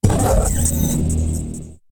attack2.ogg